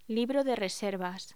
Locución: Libro de reservas